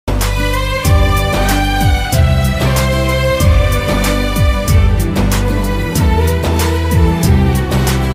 sad and emotional